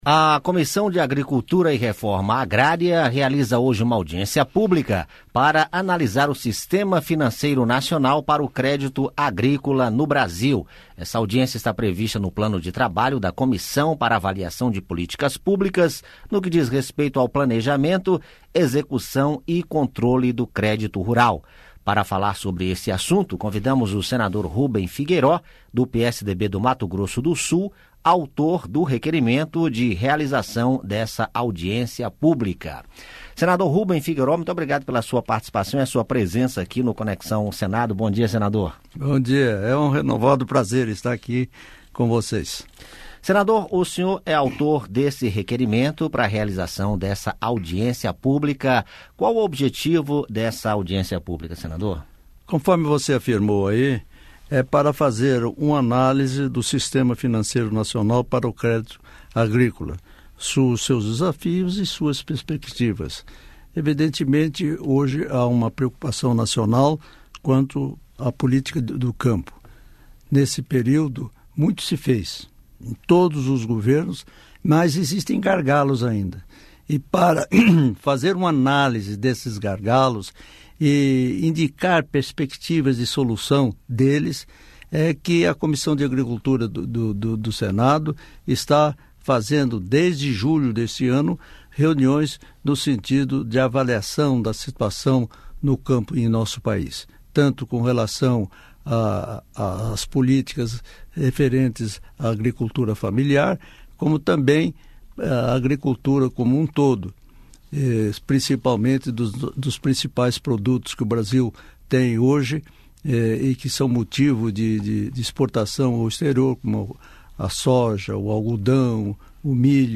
Entrevista com o senador Ruben Figueiró.